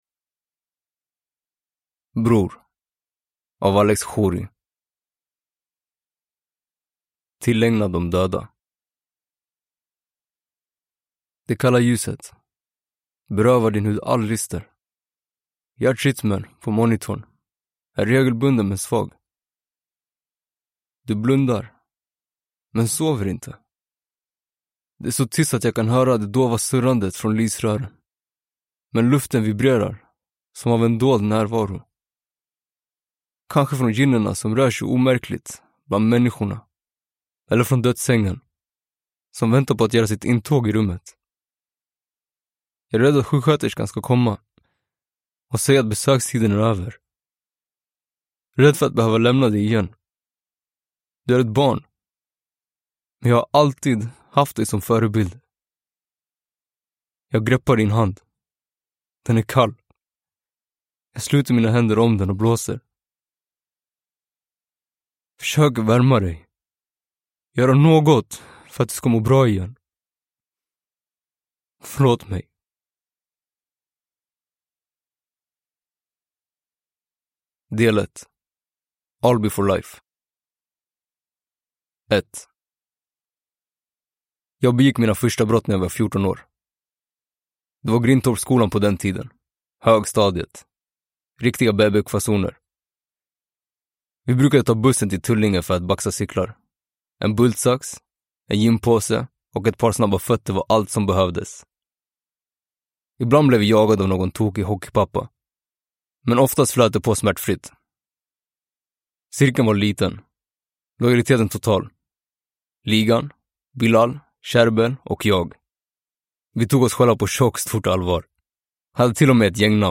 Bror – Ljudbok – Laddas ner